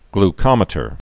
(gl-kŏmĭ-tər)